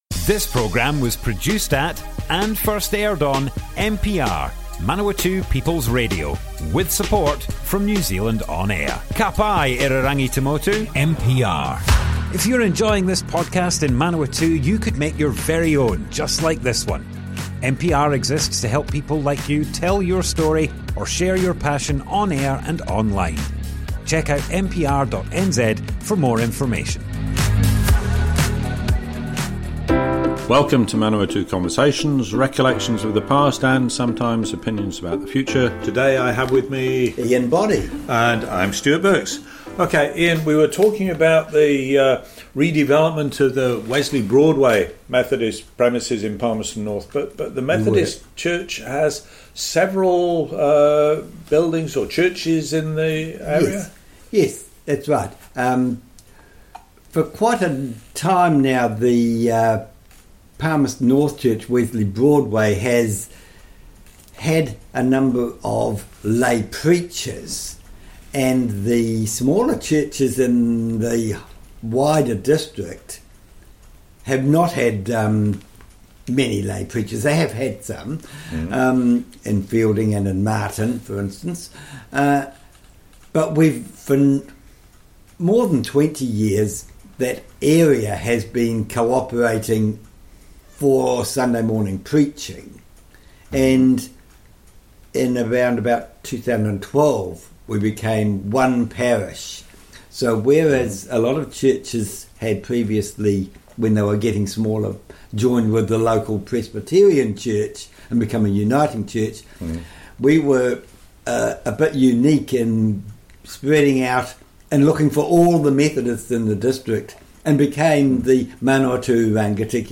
Manawatu Conversations More Info → Description Broadcast on Manawatu People's Radio, 19th November 2024.
oral history